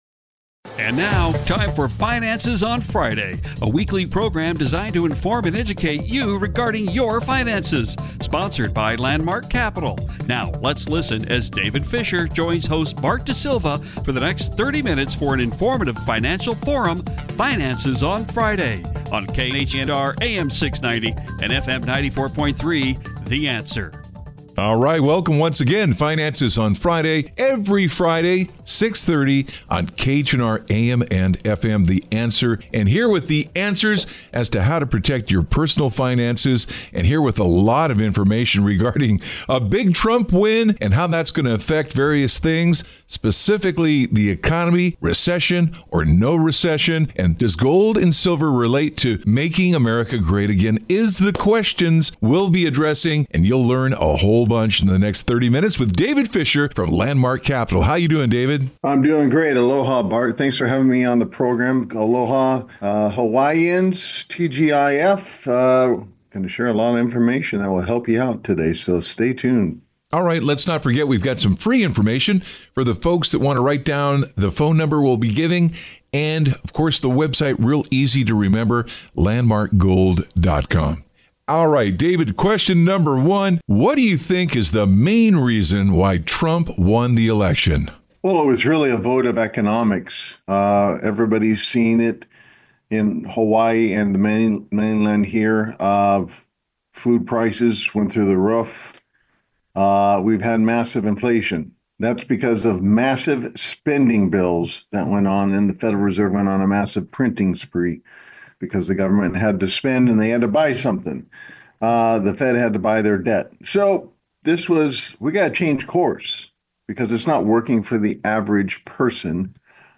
radio talk show host